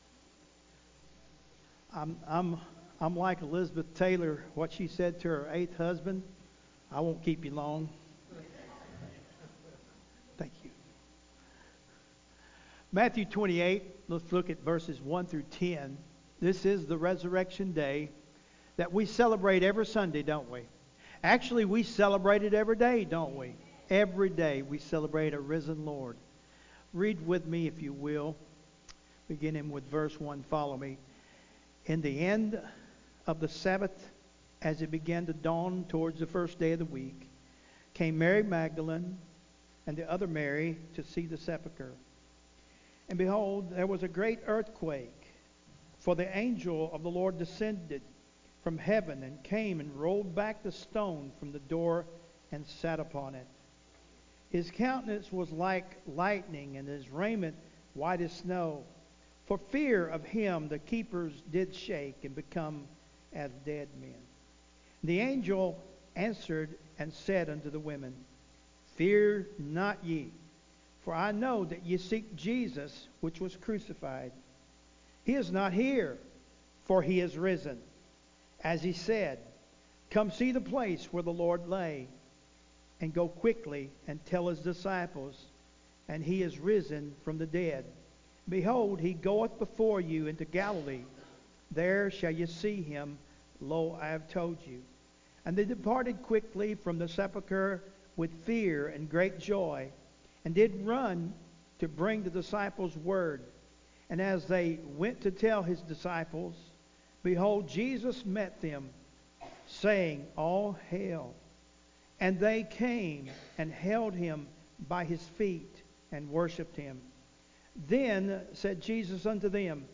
Easter Morning Service Sunday Morning Service